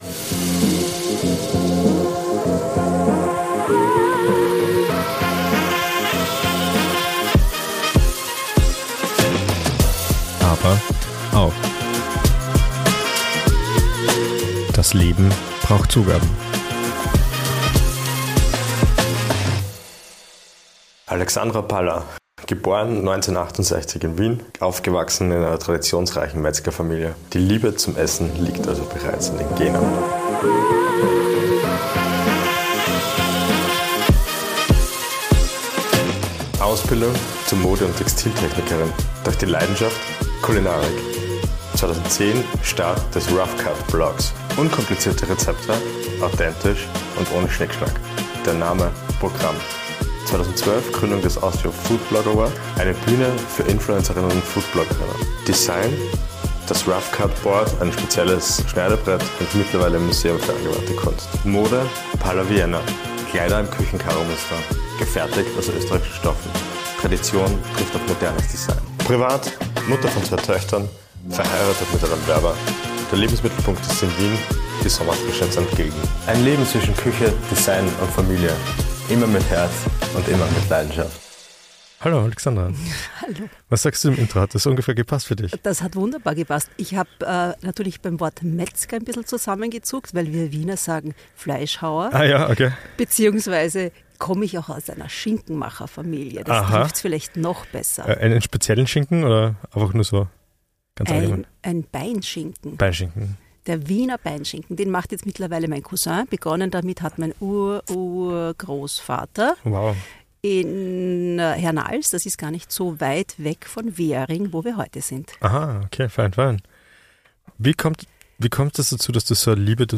Beschreibung vor 1 Jahr Für die zweite Folge von ABER AUCH hat es uns mit unserem PODMOBIL abermals nach Wien verschlagen.